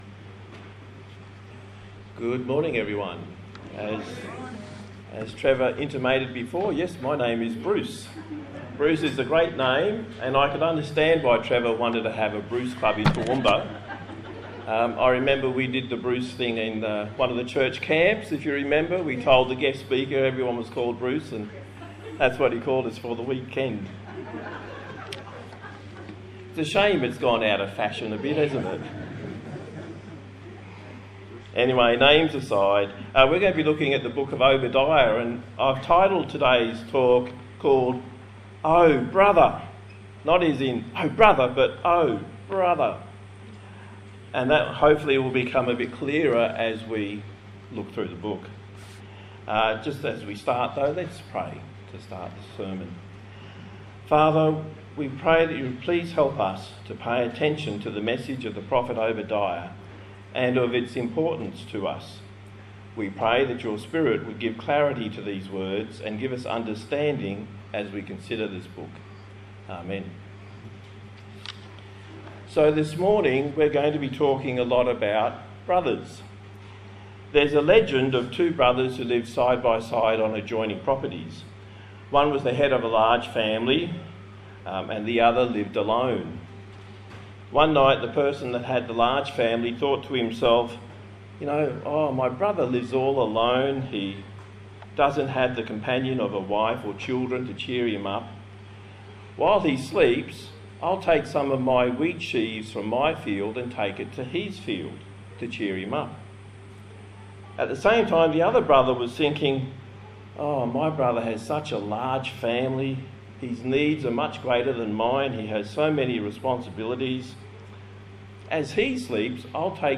Service Type: Morning Service A sermon on the book of Obadiah